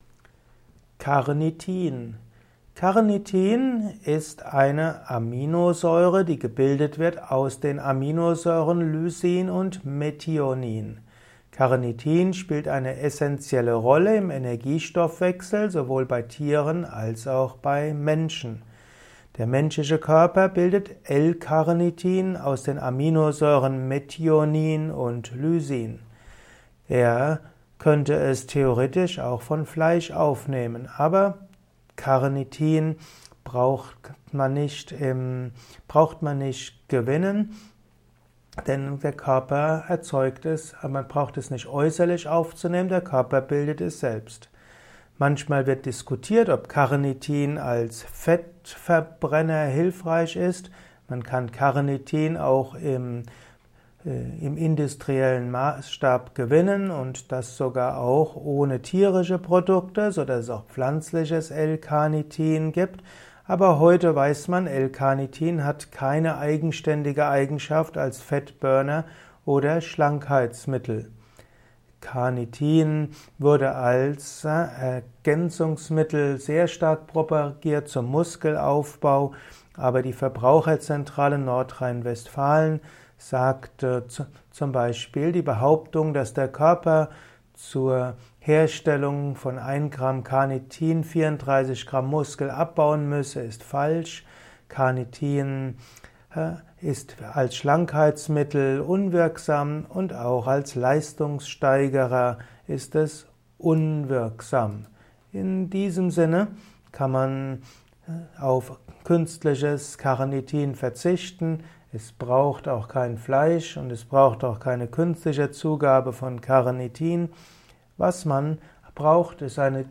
Erfahre Wissenswertes über die Aminosäure Carnitin in diesem Kurzvortrag